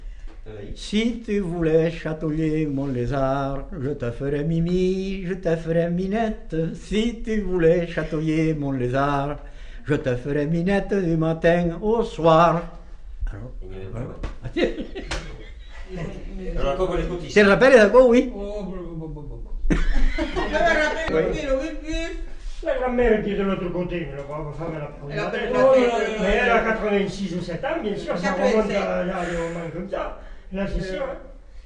Aire culturelle : Agenais
Lieu : [sans lieu] ; Lot-et-Garonne
Genre : chant
Effectif : 1
Type de voix : voix d'homme
Production du son : chanté
Danse : scottish